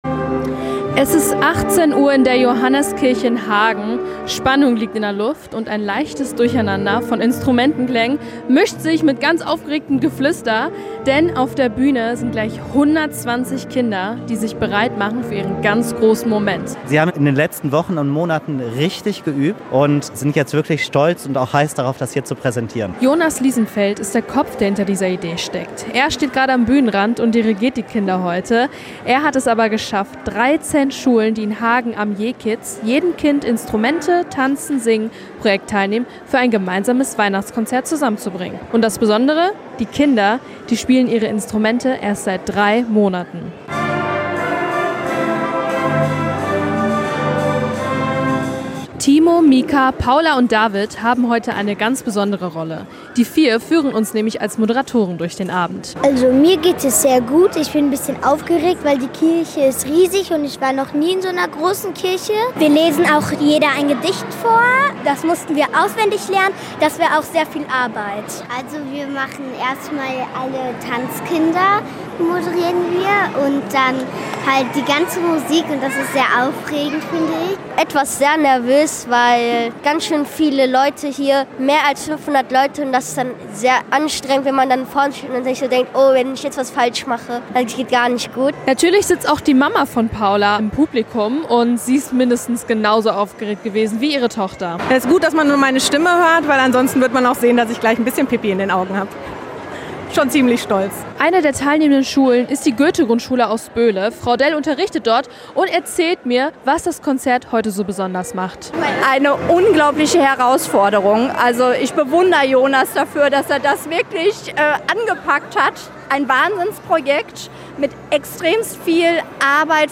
Weihnachtskonzert in der Johanniskirche
In Hagen fand heute Abend ein ganz besonderes Weihnachtskonzert statt: Auf der Bühne standen 120 Kinder aus 13 Grundschulen.
Reportage